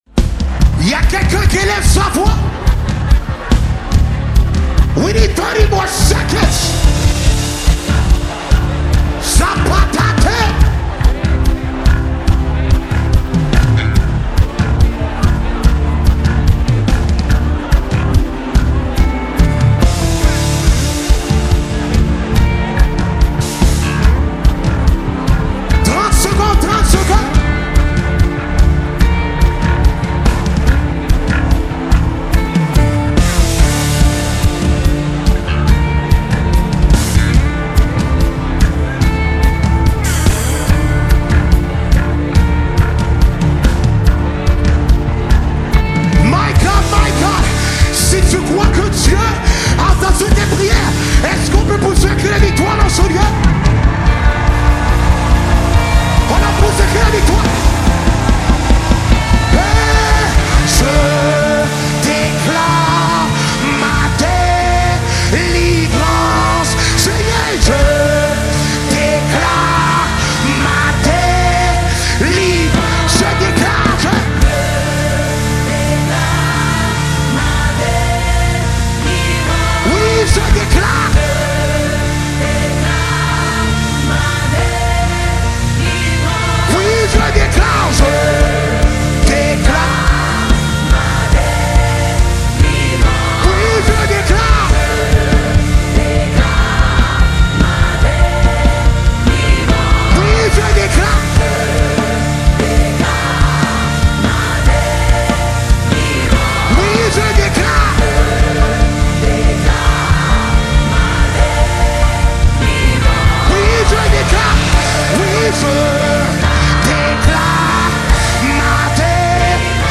contemporary worship music